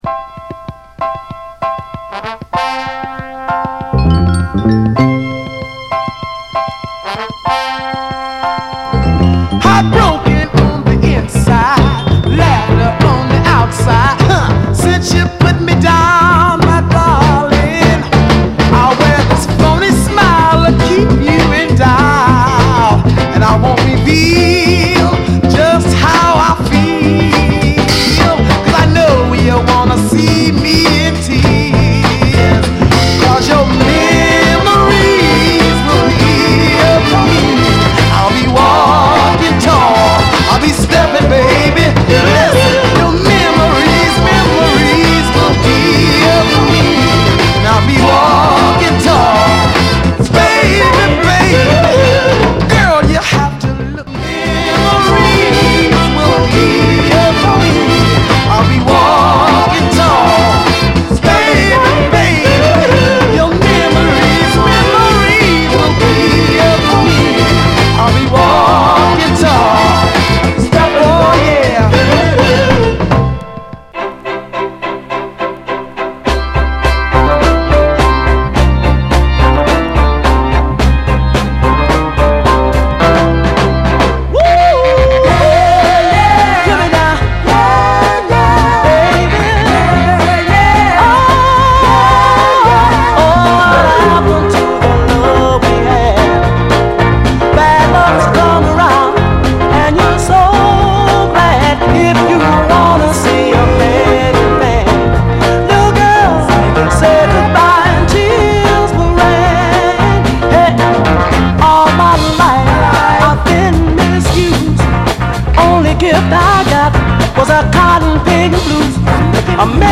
シカゴ発のヴォーカル・グループ
タイトなドラムと高揚系のホーン・アレンジがカッコいい溌剌クロスオーヴァー・ソウル
※試聴音源は実際にお送りする商品から録音したものです※